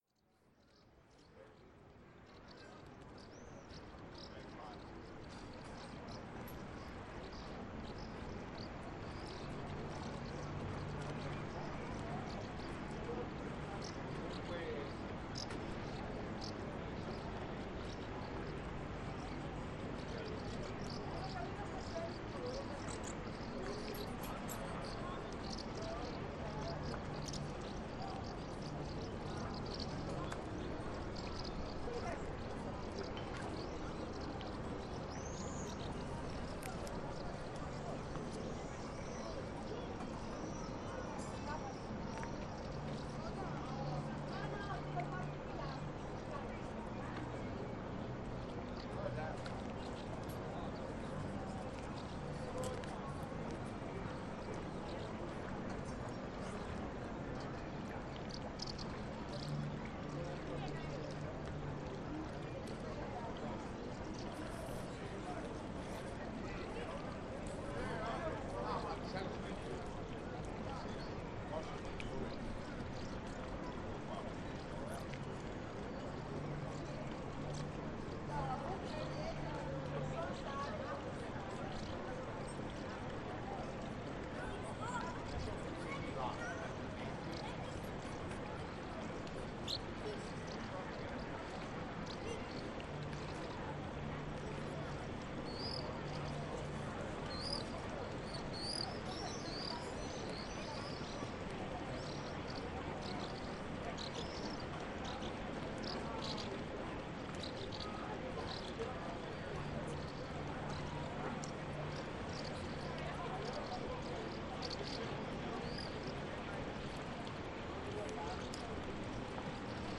City Harbour (Ambient Sound)
Ein Hafen, Vogelgezwitscher, leichte Wellen, Boote, die knattern. Menschen ziehen vorüber.
City-Harbour_128.mp3